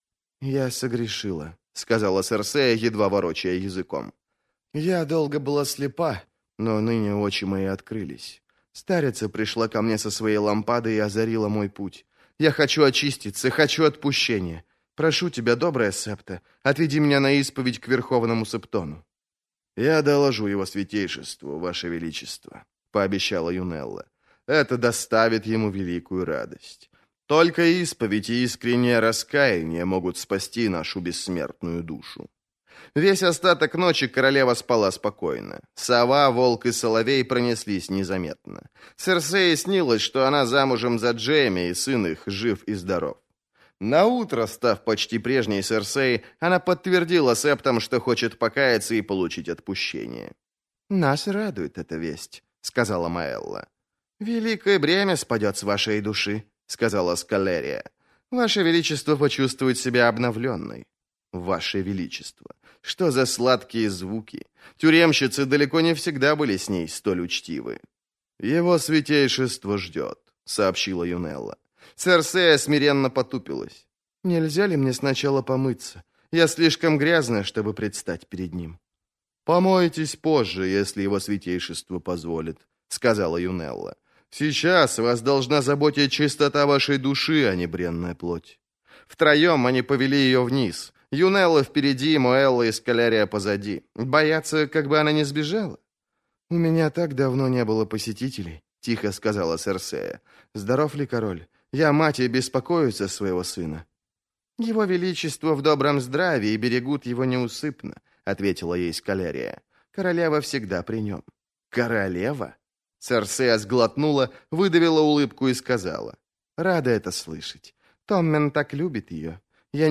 Аудиокнига Танец с драконами. Книга 2. Искры над пеплом - купить, скачать и слушать онлайн | КнигоПоиск